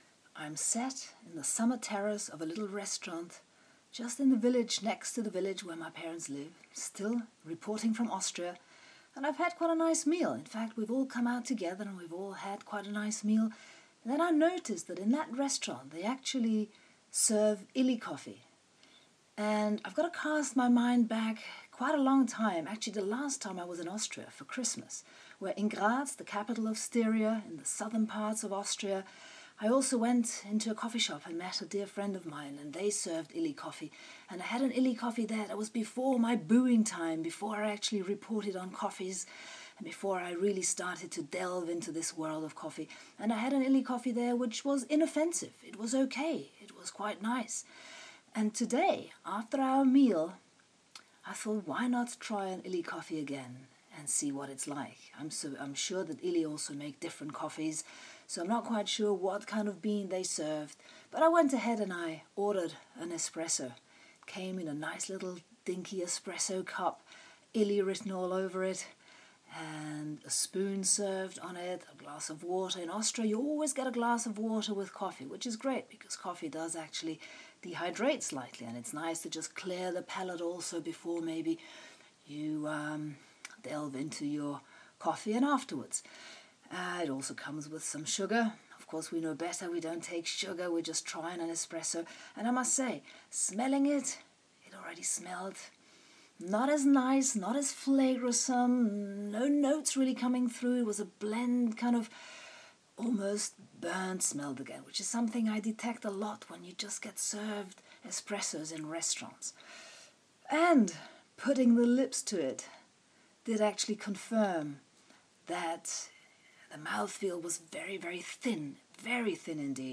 After having had lunch in a restaurant, I felt like trying an Illy espresso which is being served here in a styrian village restaurant.